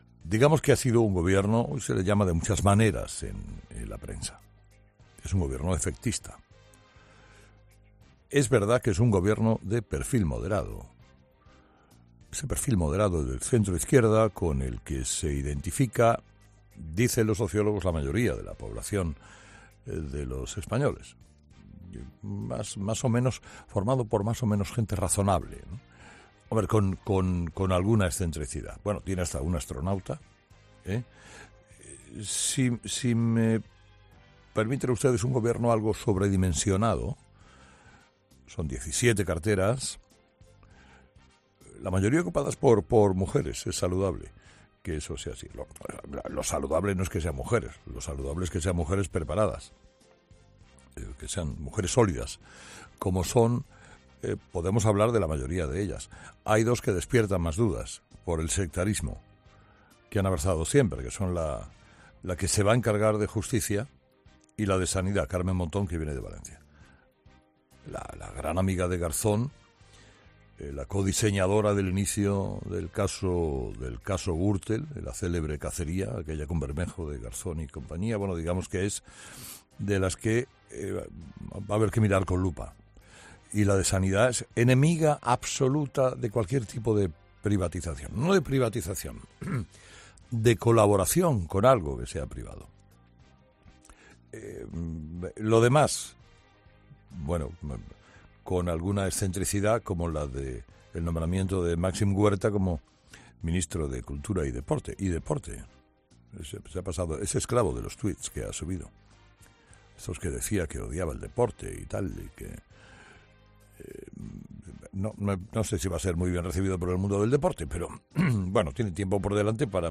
Carlos Herrera ha aprovechado su monólogo de este jueves para analizar los nombramientos de ministros para el gobierno de Pedro Sánchez.